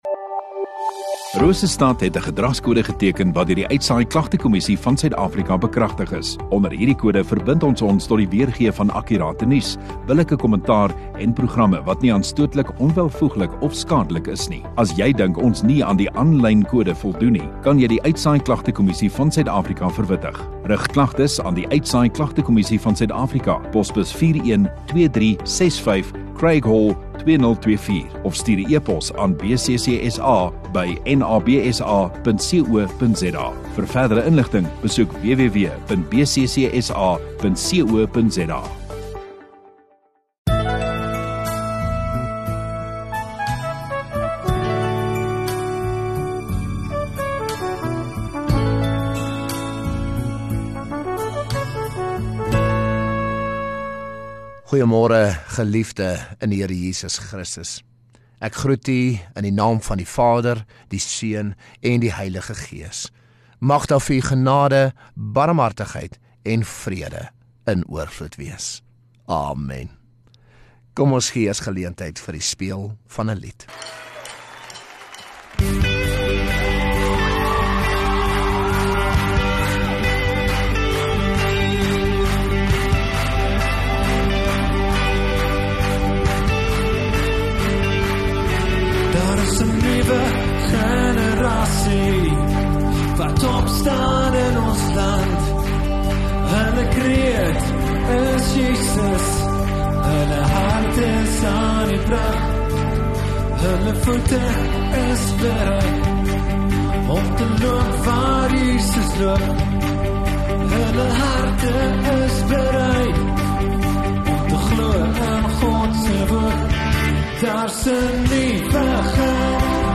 22 Sep Sondagoggend Erediens